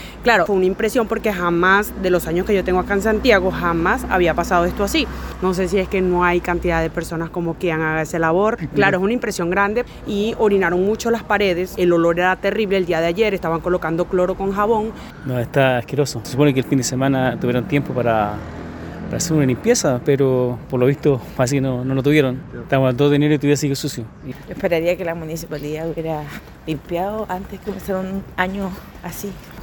Residentes y transeúntes del sector se refirieron a esta situación, criticando la falta de limpieza.
cu-gente-centro-basura.mp3